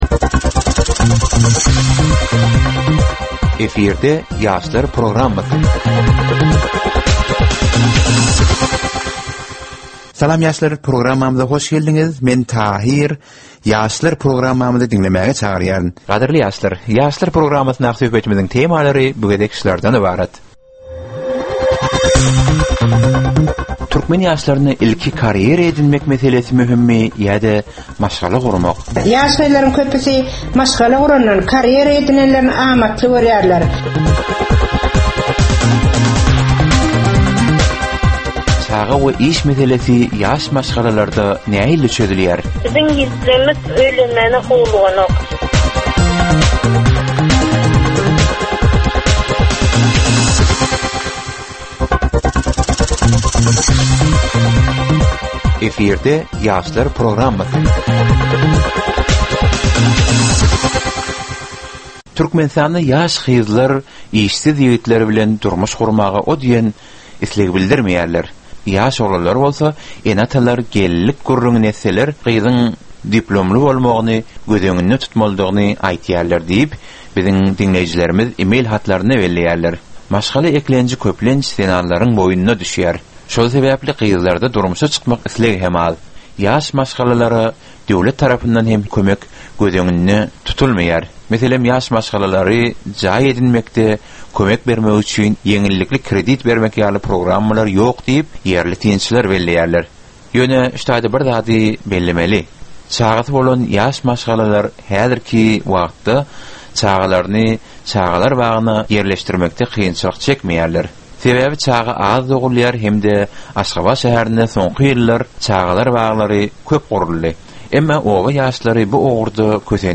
Türkmen we halkara yaşlarynyň durmuşyna degişli derwaýys meselelere we täzeliklere bagyşlanylyp taýýarlanylýan 15 minutlyk ýörite gepleşik. Bu gepleşikde ýaslaryň durmuşyna degişli dürli täzelikler we derwaýys meseleler barada maglumatlar, synlar, bu meseleler boýunça adaty ýaşlaryň, synçylaryň we bilermenleriň pikrileri, teklipleri we diskussiýalary berilýär. Gepleşigiň dowamynda aýdym-sazlar hem eşitdirilýär.